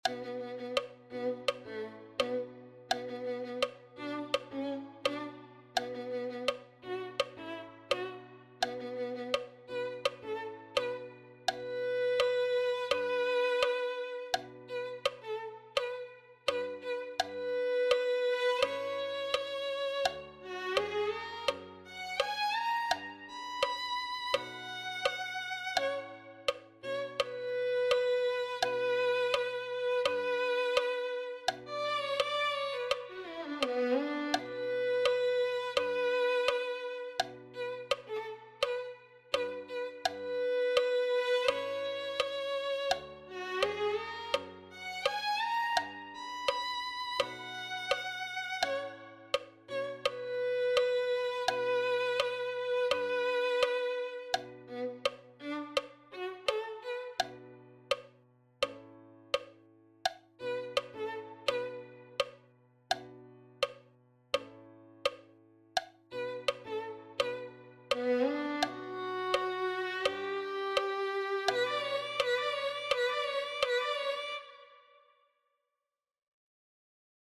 spirituál